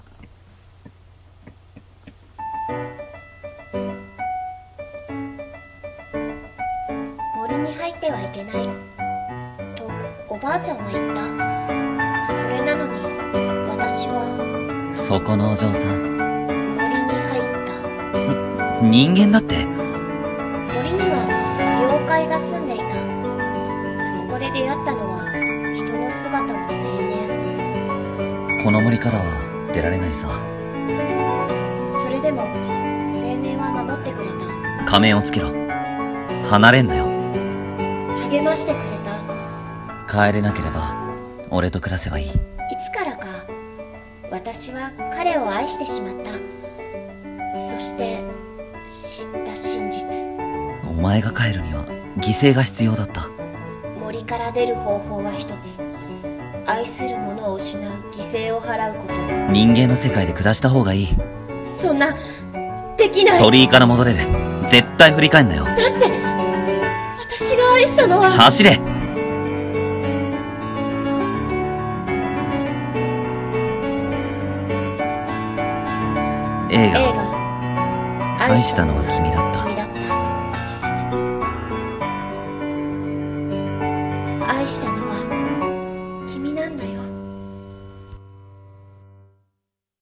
【映画予告風声劇】愛したのは君だった 声劇